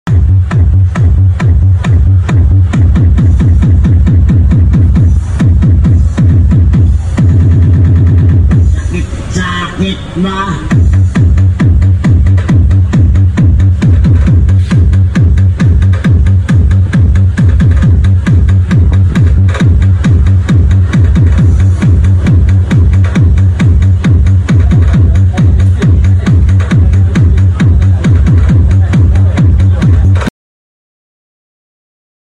Mohawk MS Series 10” Double subwoofer